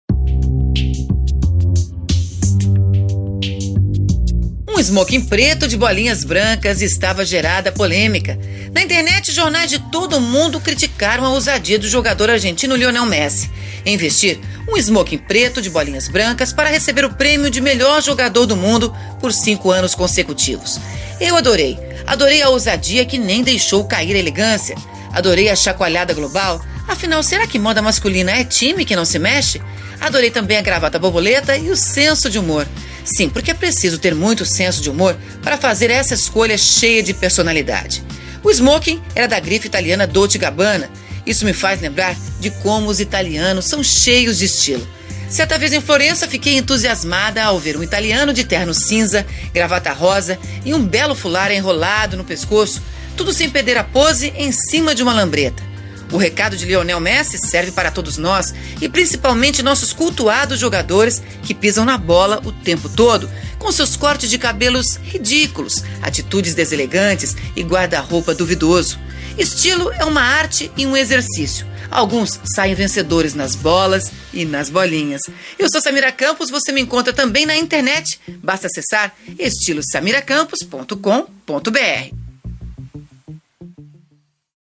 Confira o comentário da jornalista de moda